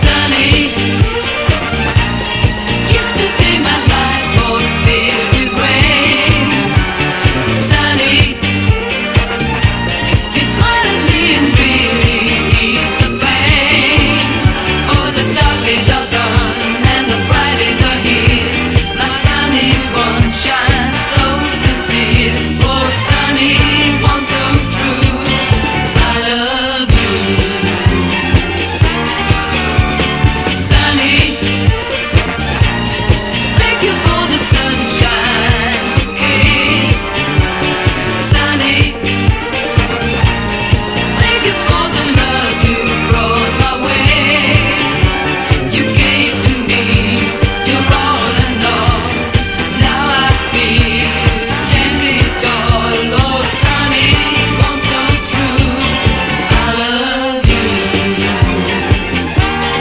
SOUL / SOUL / RHYTHM & BLUES / VOCAL (US)
53年発表のレディR&B名曲！